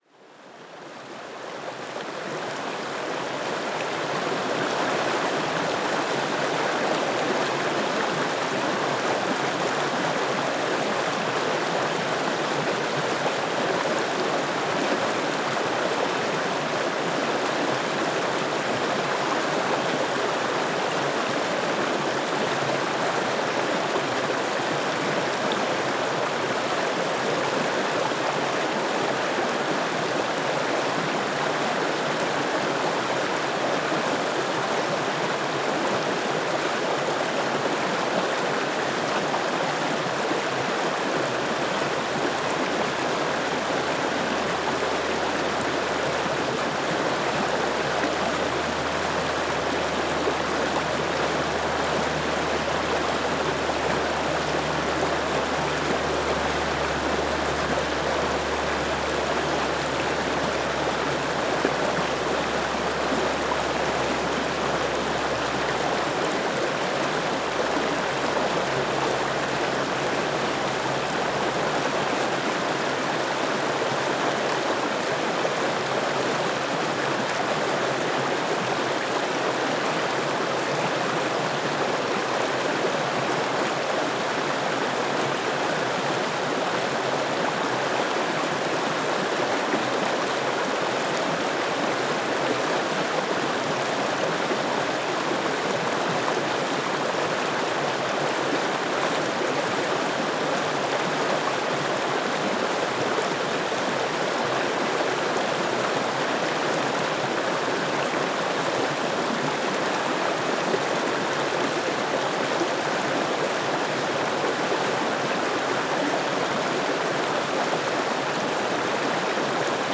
Paisagem sonora de escoamento de água de ribeiro, Sanguinhedo de Maçãs a 19 Fevereiro 2016.
Escoamento de água do ribeiro a ESTE do rio Vouga junto a campo de pastoreio.